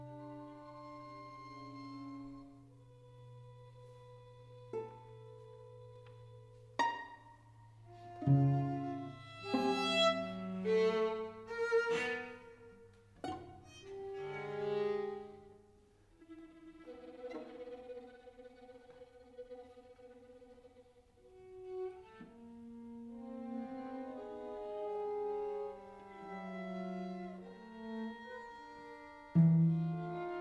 0 => "Musique de chambre"